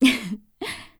cleric_f_voc_social_02.wav